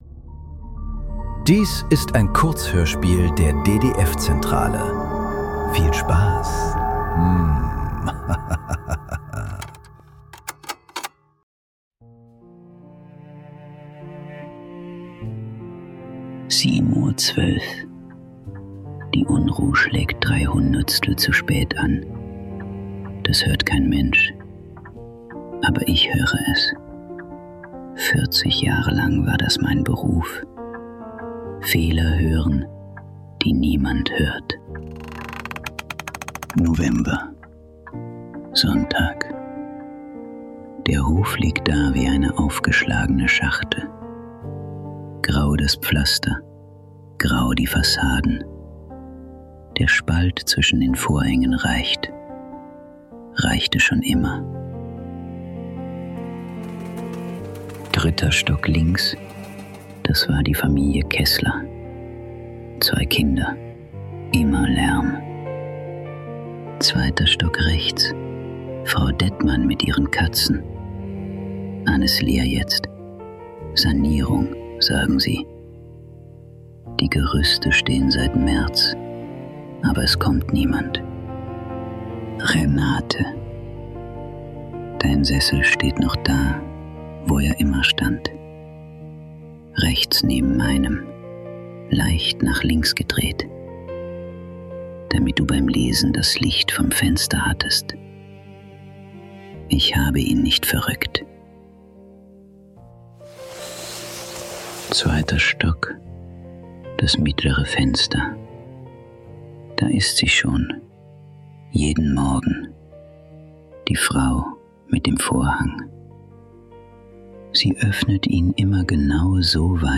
Kurzhörspiele. Leise.
Zwei einsame Menschen beobachten einander über einen Berliner Innenhof ohne zu ahnen, dass sie längst füreinander sorgen. Ein stilles Kammerspiel über das Sehen und Gesehenwerden.